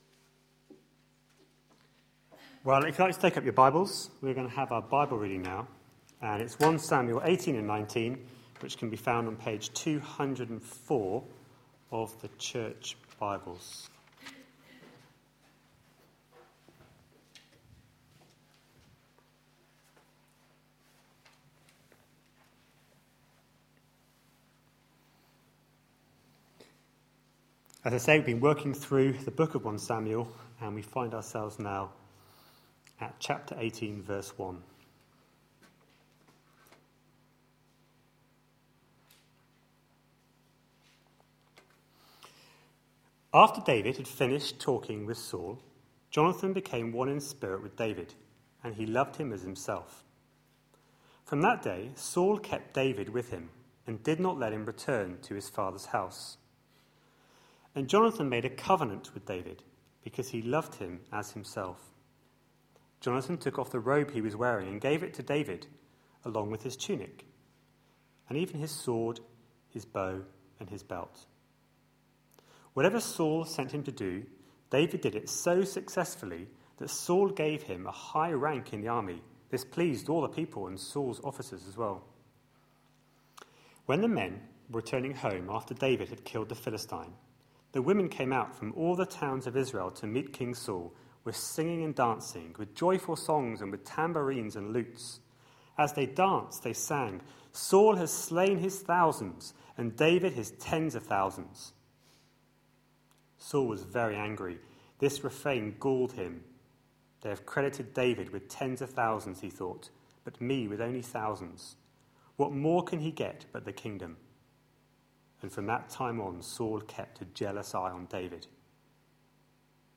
A sermon preached on 14th July, 2013, as part of our God's King? series.